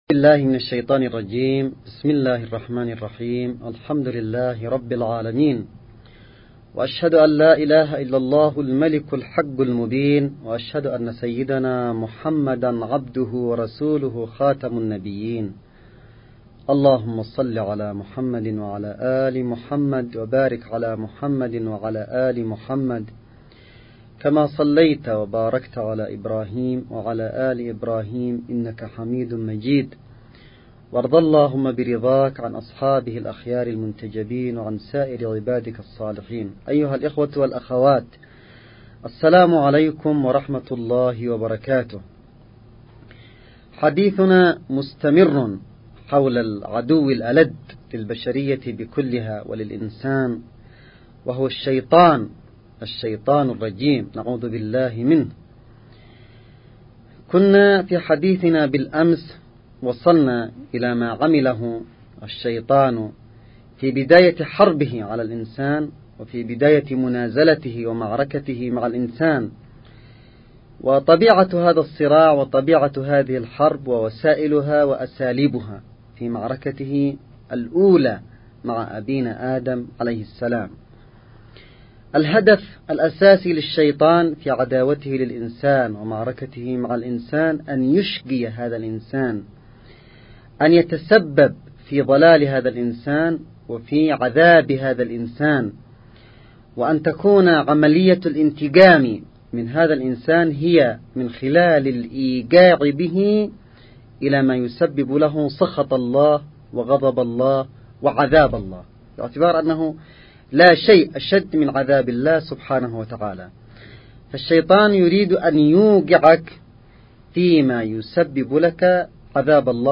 نص + أستماع للمحاضرة الرمضانية السادسة للسيد عبدالملك بدر الدين الحوثي.
المحاضرة_الرمضانية_السادسة_للسيد.mp3